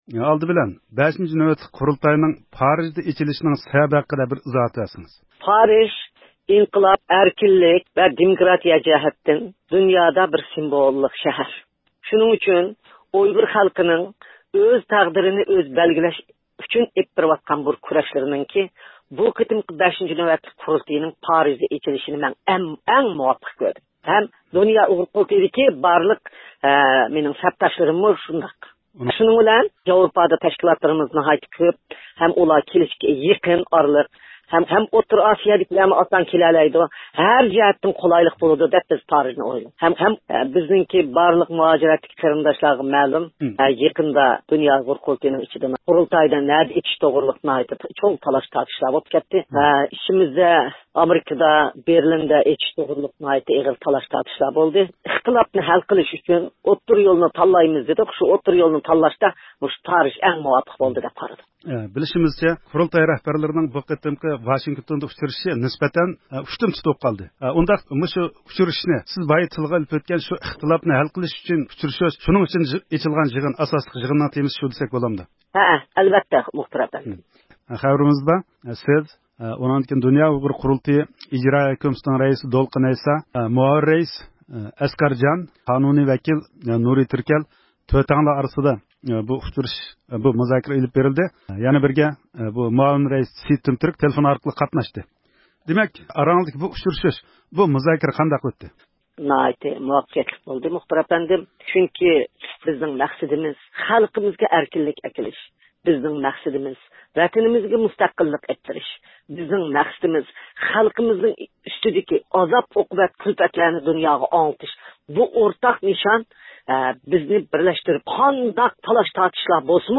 دۇنيا ئۇيغۇر قۇرۇلتىيىنىڭ مۇھىم رەھبەرلىرى 28 – فېۋرالدىن 1 – مارتقا قەدەر ۋاشىنگتوندا ئۇچرىشىپ، 5 – نۆۋەتلىك قۇرۇلتاينى پارىژدا ئېچىشنى قارار قىلغاندىن كېيىن، مۇخبىرىمىز بۈگۈن قۇرۇلتاي رەئىسى رابىيە قادىر خانىمنى زىيارەت قىلىپ، بۇ قېتىمقى يىغىن ۋە پارىژدا ئېچىلغۇسى قۇرۇلتاي ھەققىدە مەلۇمات سورىدى. رابىيە خانىم 5 – نۆۋەتلىك قۇرۇلتاينىڭ ئەھمىيىتى ۋە پارىژدا ئېچىلىشىنىڭ سەۋەبلىرى ئۈستىدە نۇقتىلىق توختالدى.